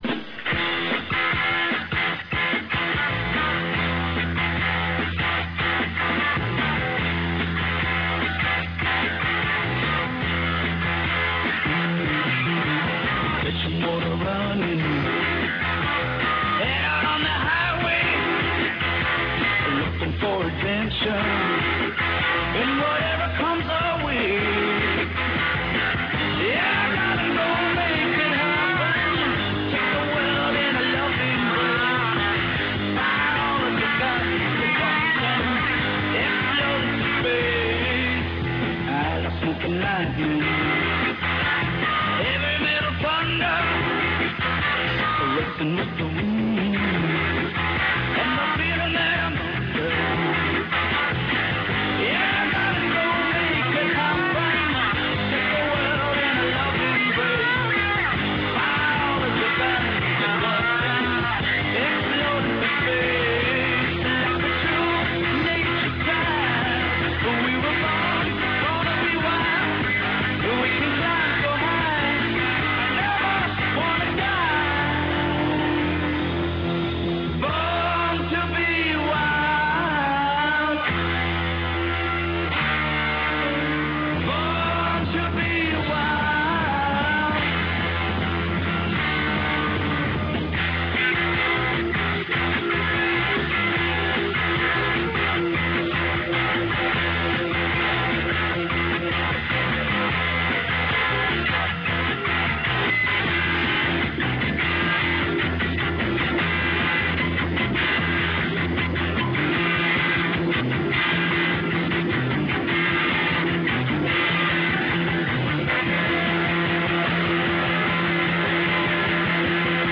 Cactus Jack --WCW Cactus Jack --ECW Cactus Jack --WWF Mankind --WWF Dudelove --WWF Interviews/Speeches Cactus Jack Interview Interview given by Cactus Jack shortly before a barbed wire death match with Terry Funk .